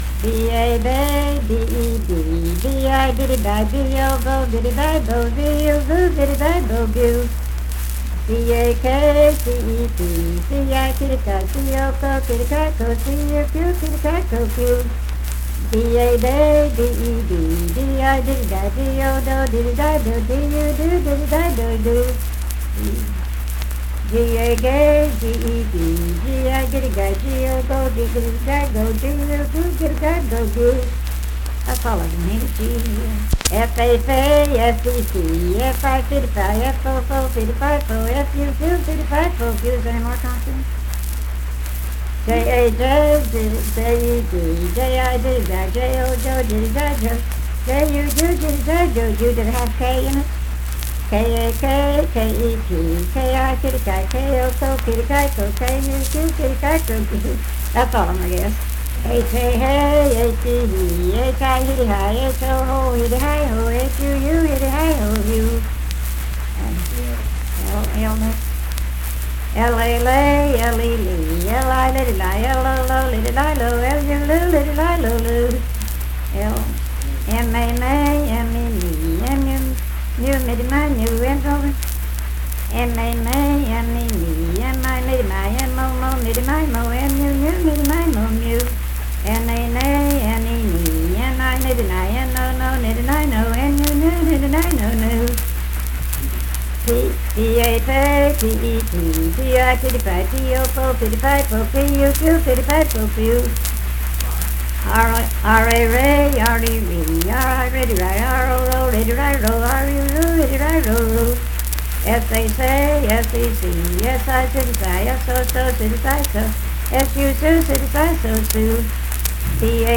Unaccompanied vocal music performance
Dance, Game, and Party Songs
Voice (sung)
Jackson County (W. Va.)